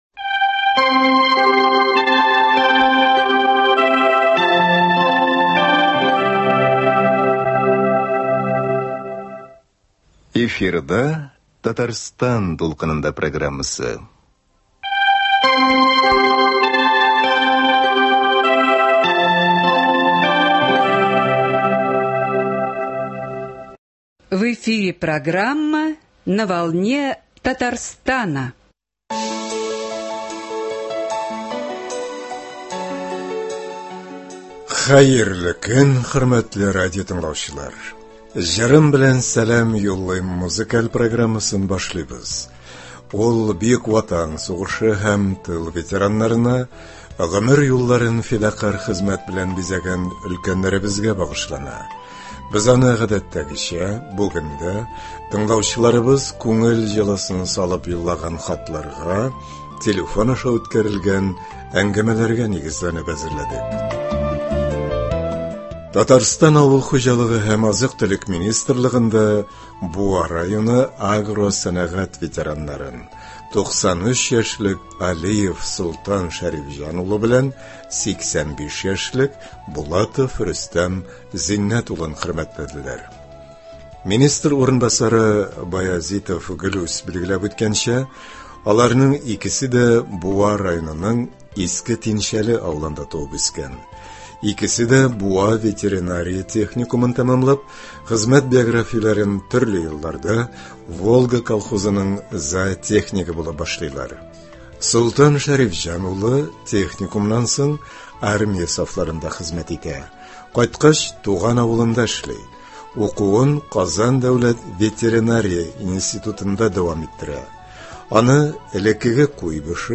музыкаль программасы